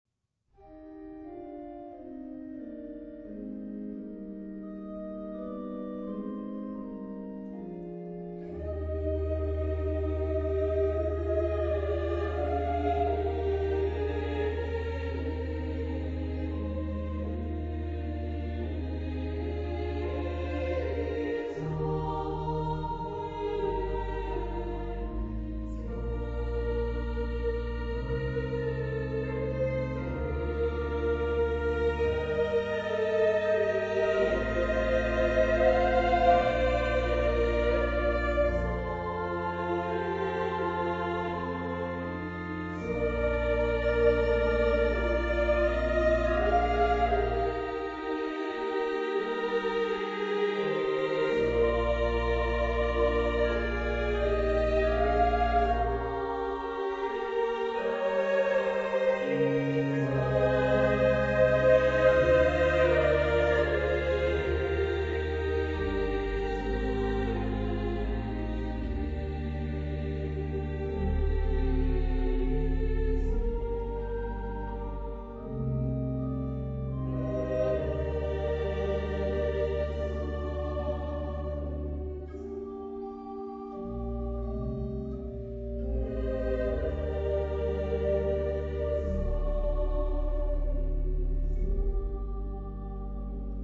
Genre-Style-Forme : Romantique ; Sacré ; Messe
Type de choeur : SSA  (3 voix égales )
Instruments : Orgue (1)
Tonalité : mi bémol majeur